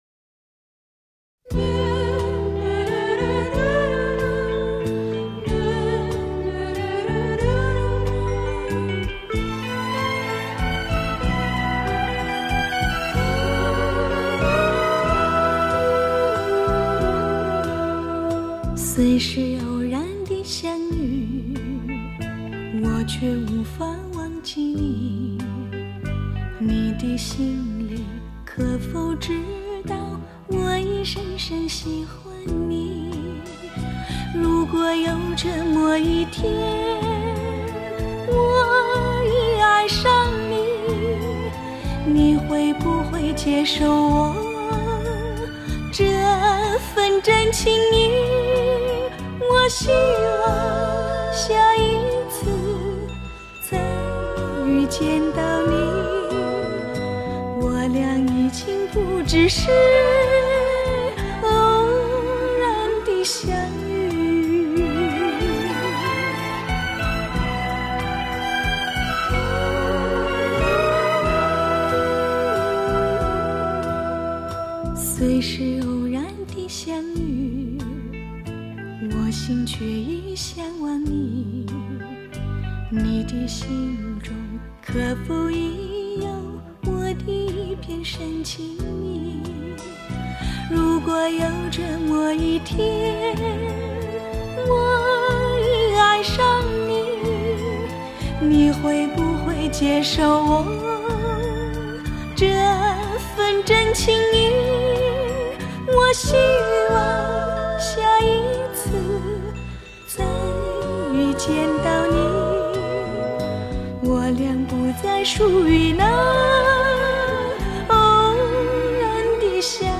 （低音质mp3）